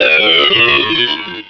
pokeemerald / sound / direct_sound_samples / cries / regice.aif